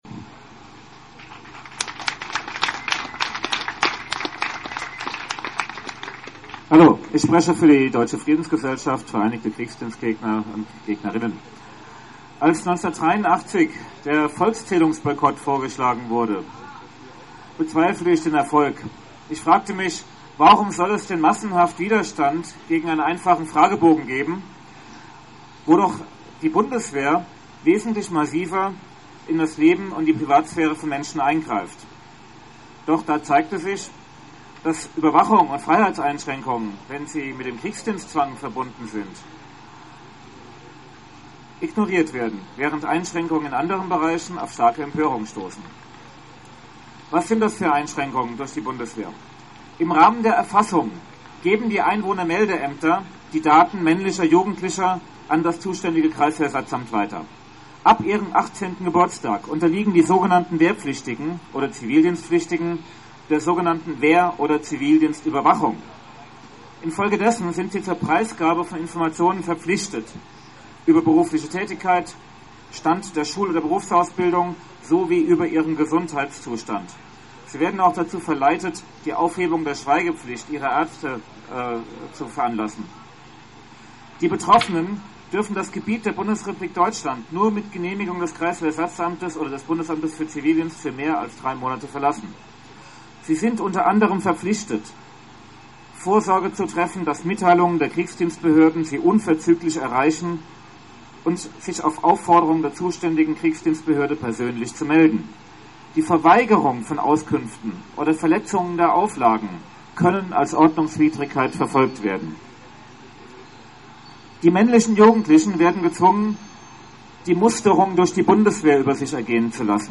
DFG-VK Mainz: Demonachlese "Freiheit statt Angst" (6.6.09) - Rede der DFG-VK Mainz